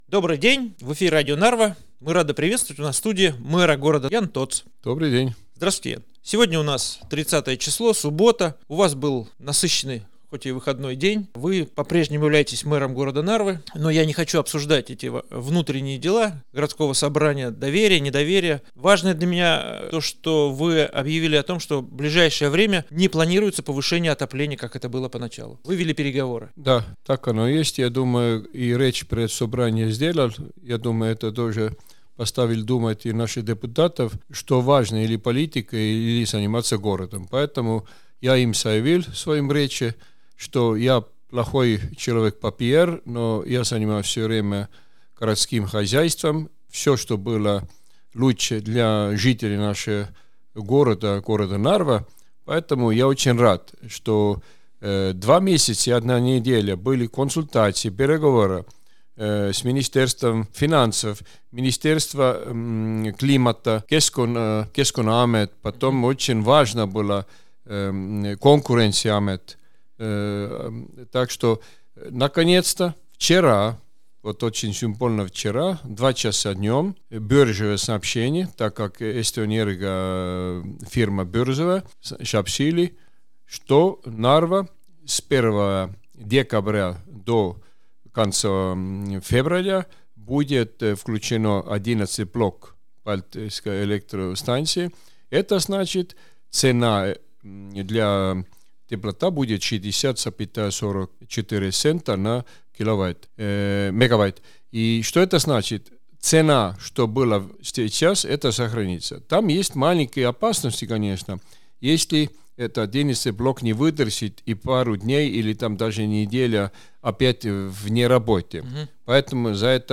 После несостоявшегося вотума недоверия действующему мэру Нарвы Яану Тоотсу мы пригласили его в радиостудию, где он рассказал о текущей работе и перспективе и следующих задачах, над которыми работает управа.